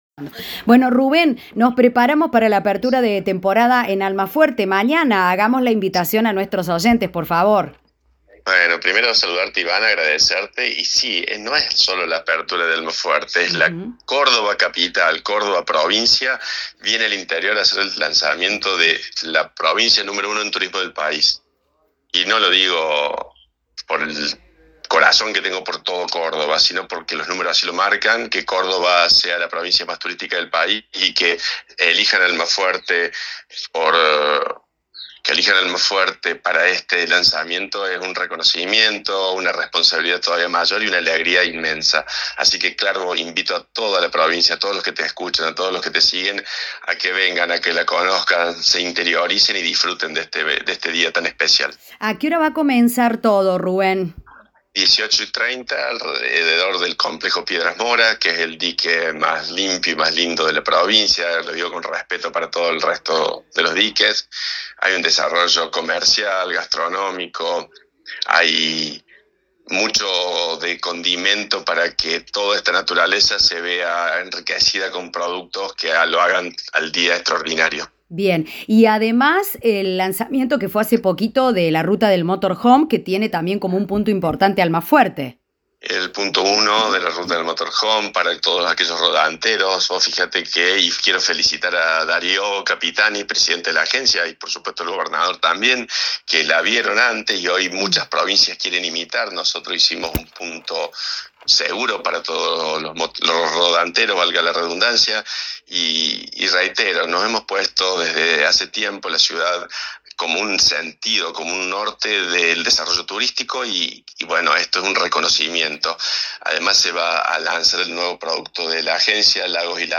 Ruben-Dagum-Intendente-de-Almafuerte-Turismo-2026.mp3